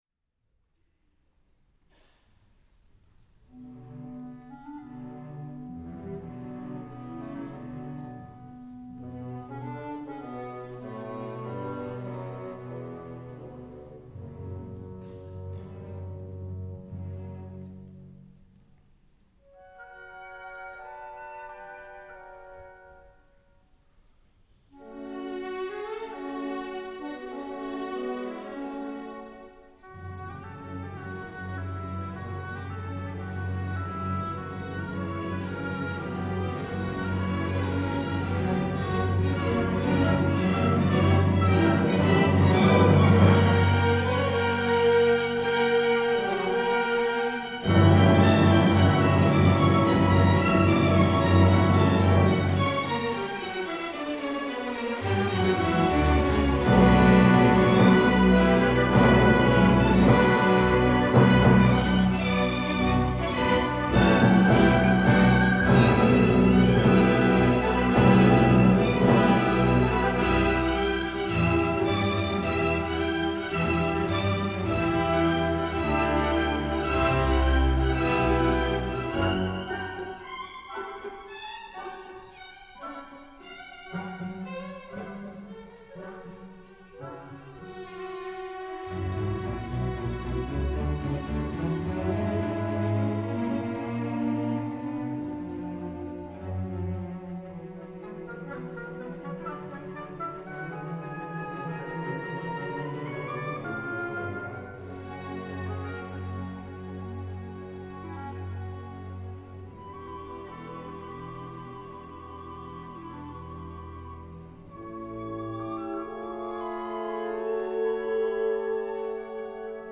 Antonin Dvorak: Concierto en si menor para violonchelo y orquesta, Op. 104, B.191